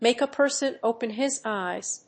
アクセントmàke a person ópen his éyes